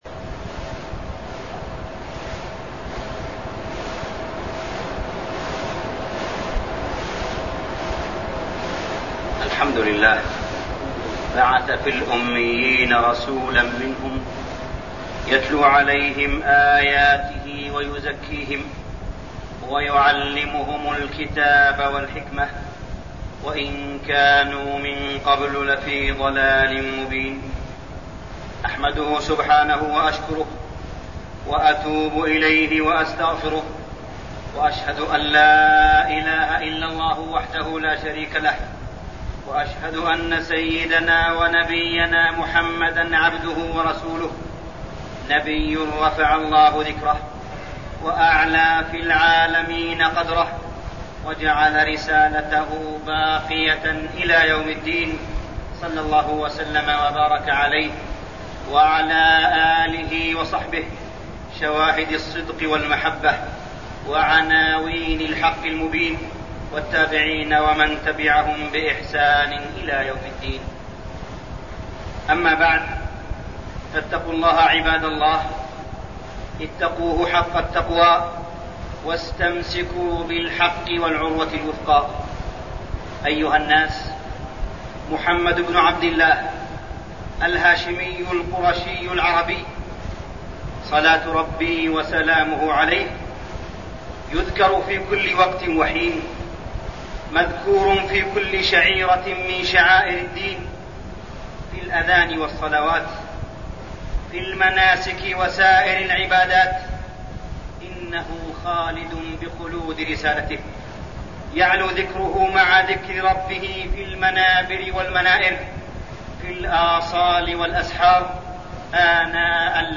تاريخ النشر ٢٥ صفر ١٤١١ هـ المكان: المسجد الحرام الشيخ: معالي الشيخ أ.د. صالح بن عبدالله بن حميد معالي الشيخ أ.د. صالح بن عبدالله بن حميد التضرع إلى الله